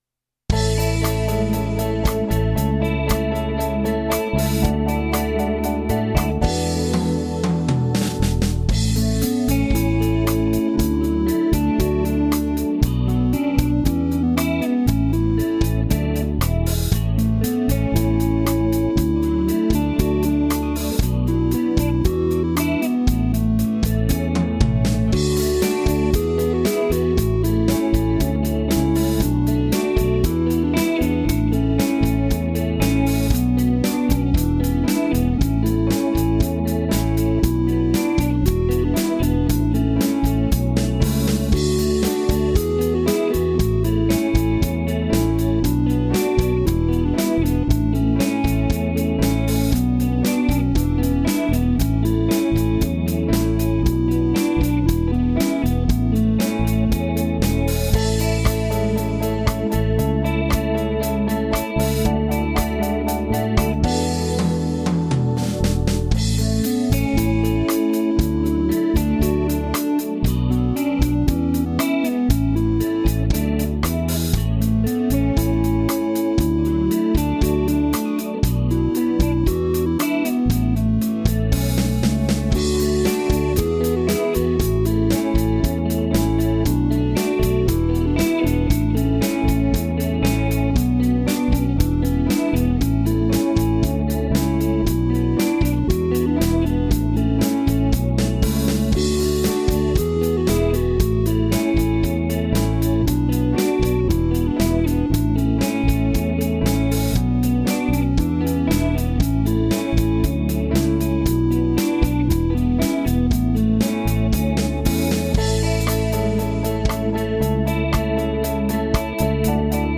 hymn__z_subtelna_linia_melodyczna.mp3